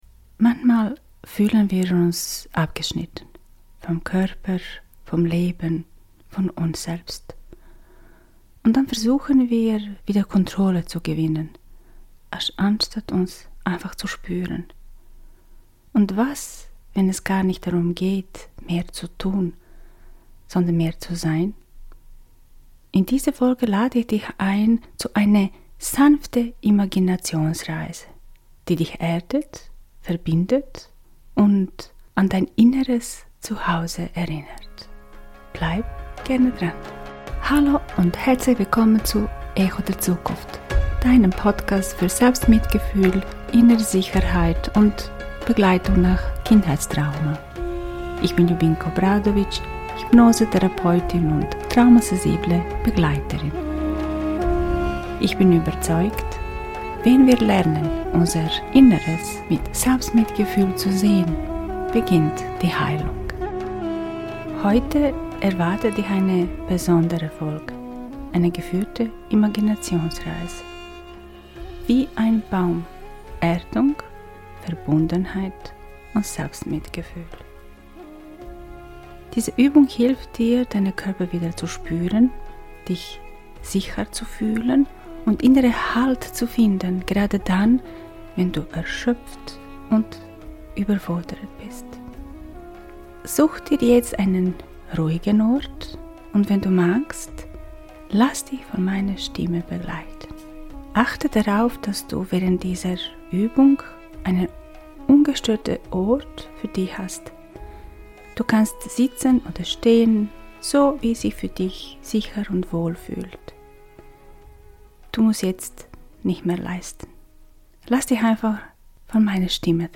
Eine geführte Imaginationsreise, die dich mit deinem Körper verbindet und dir hilft, wieder Sicherheit, Erdung und inneren Halt zu spüren. Spüre dich wie ein Baum – verwurzelt, getragen und liebevoll verbunden mit dir selbst.